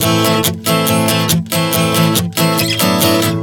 Strum 140 Am 07.wav